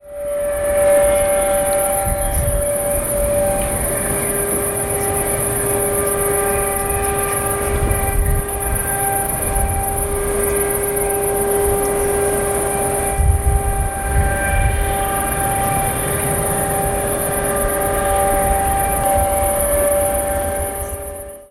Bohrgeräusche am Tagebau Hambach bei Morschenich (Audio 1/1) [MP3]
rwe-prueft-erhalt-hambacher-forst-kohlebagger-bohrgeraeusche.mp3